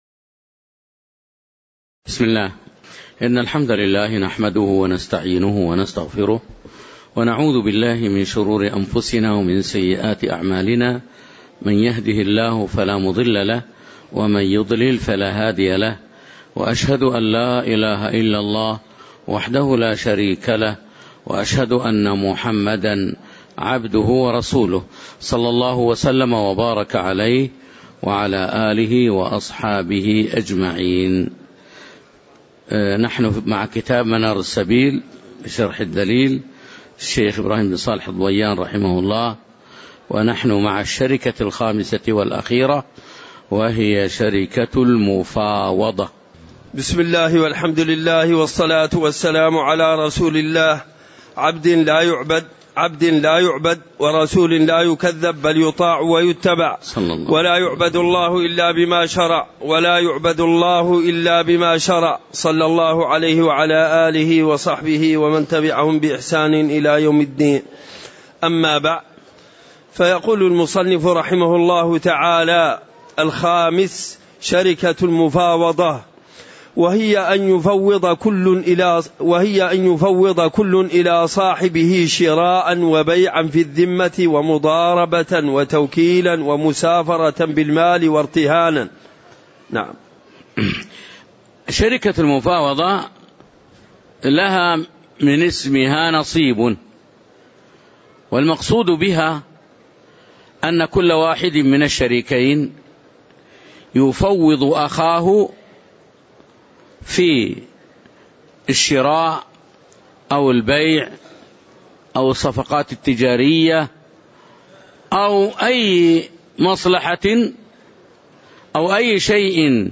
تاريخ النشر ١ جمادى الأولى ١٤٤١ هـ المكان: المسجد النبوي الشيخ